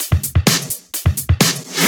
Index of /VEE/VEE Electro Loops 128 BPM
VEE Electro Loop 191.wav